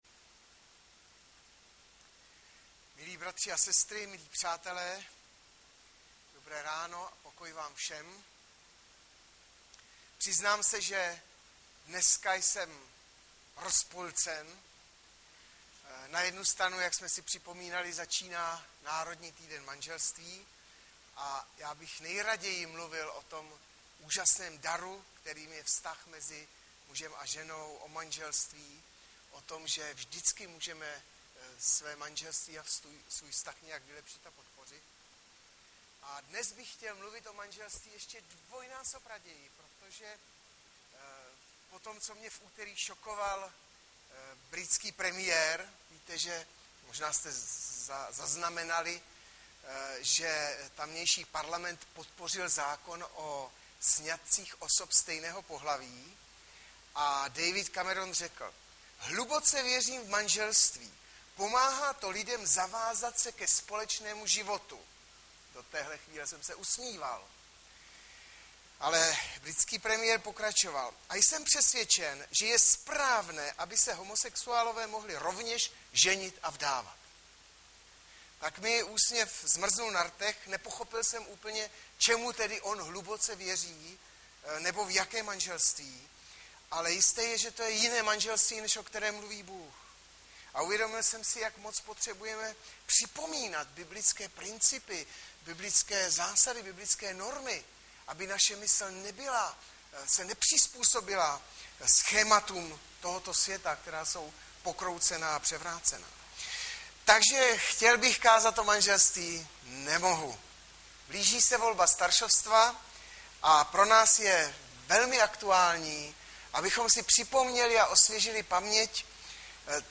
Hlavní nabídka Kázání Chvály Kalendář Knihovna Kontakt Pro přihlášené O nás Partneři Zpravodaj Přihlásit se Zavřít Jméno Heslo Pamatuj si mě  10.02.2013 - SLUŽBA STARŠÍCH SBORU - 1Tim 3,1-7 Audiozáznam kázání si můžete také uložit do PC na tomto odkazu.